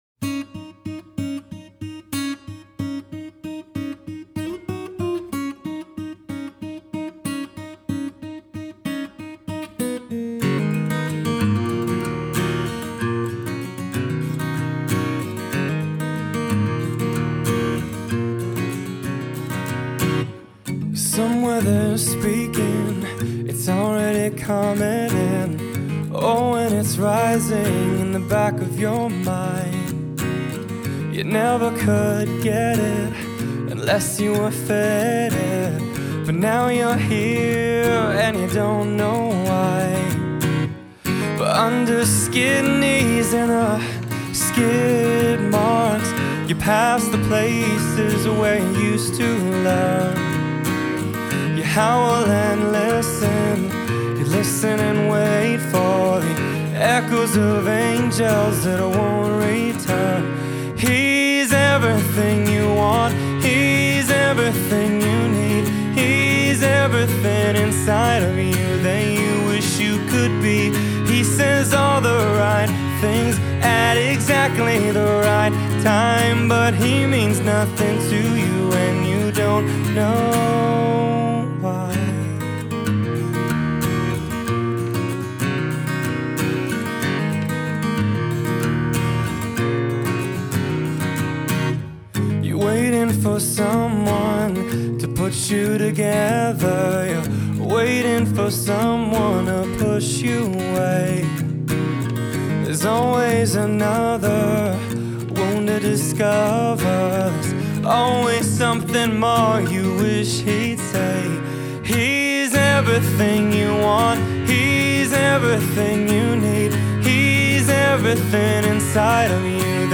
Genre: Pop-Rock, Soft-Rock, Acoustic.